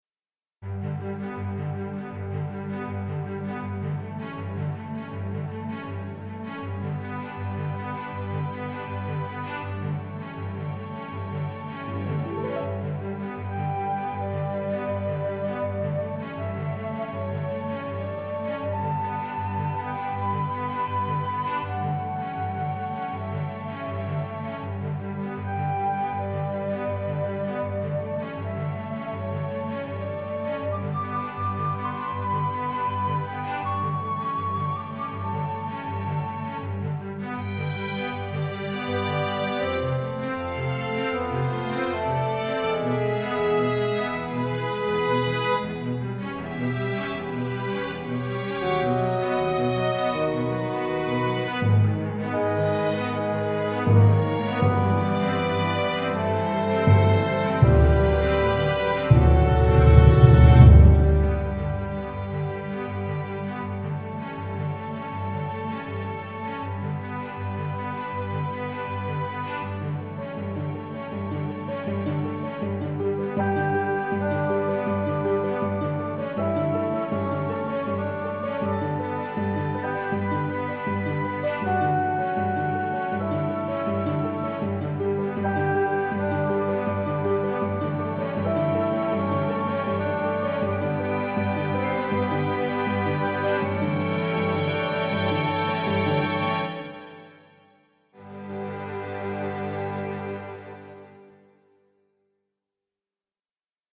Composition 6 : Nos Premières Vacances : j'aime quand la musique raconte une histoire et là c'est exactement ça : une piste facilement utilisable dans un jeu type RPG pour accompagné un récit, une aventure et donc parfaite pour se remémorer ses premières vacances ! Le son est très positif ...